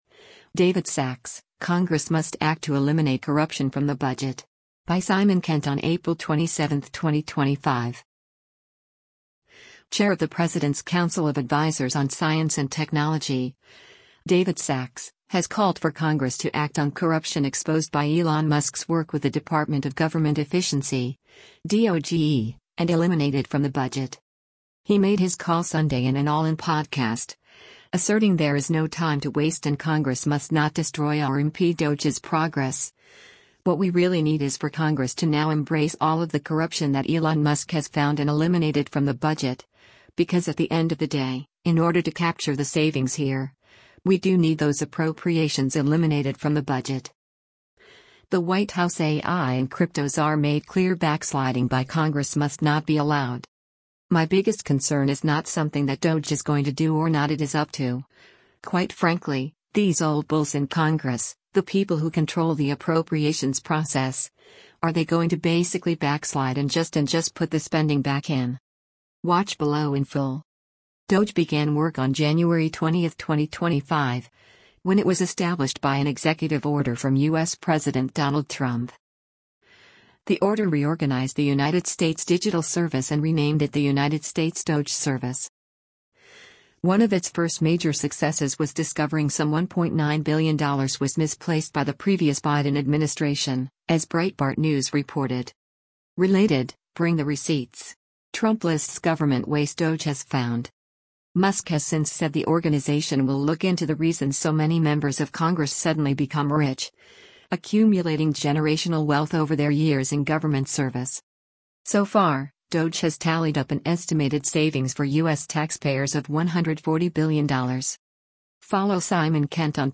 He made his call Sunday in an All-In podcast, asserting there is no time to waste and Congress must not destroy or impede DOGE’s progress: “What we really need is for Congress to now embrace all of the corruption that Elon Musk has found and eliminate it from the budget, because at the end of the day, in order to capture the savings here, we do need those appropriations eliminated from the budget.”